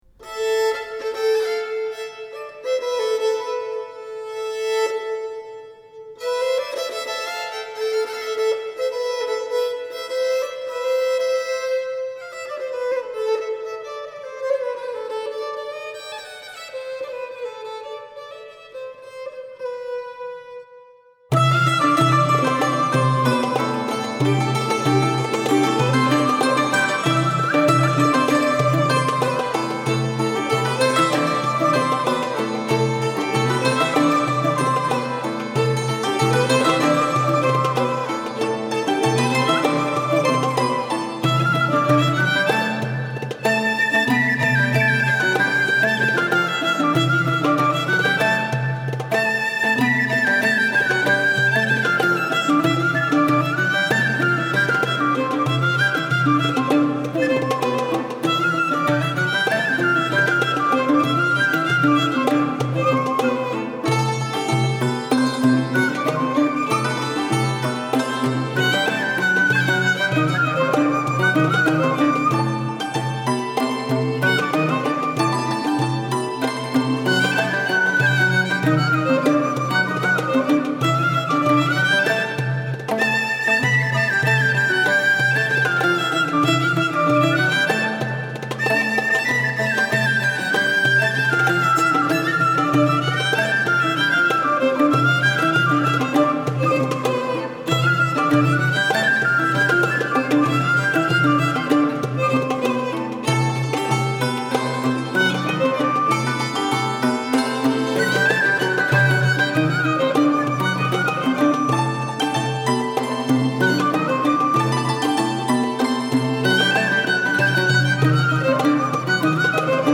Karsilamas
La cour des Lusignan à Chypre et les musiques de tradition de son entourage.
Les solistes de la Camerata Vocale et les musiciens du Concert dans l’Œuf.